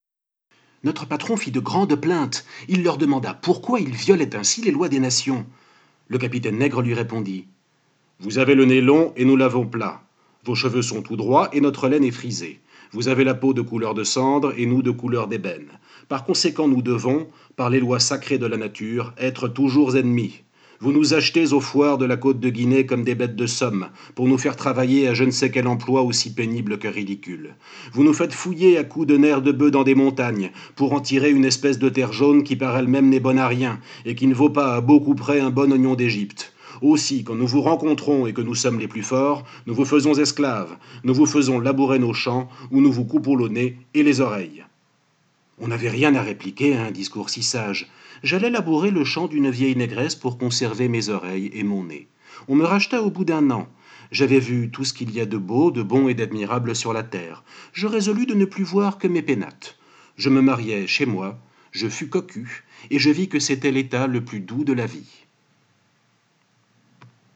Pour l’occasion, et parce que j’ai vraiment aimé ce que j’ai lu, plutôt que juste en reproduire les photos j’ai choisi de vous lire les pages en question :